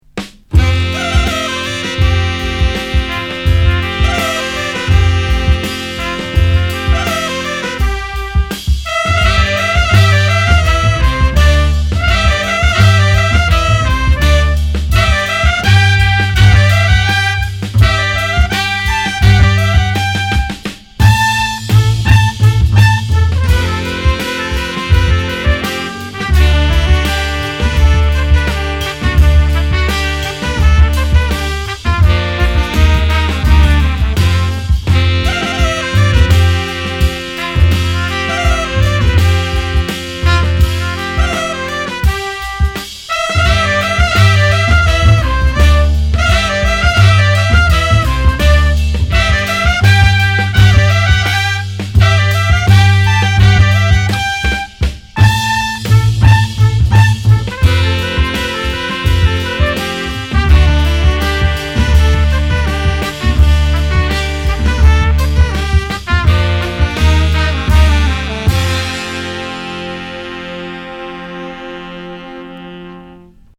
磨き上げられたジャズアンサンブルから、フォークロアの息づかいが鮮やかに立ち上がってくる名曲ずらり収録。
キーワード：フォーク　即興　北欧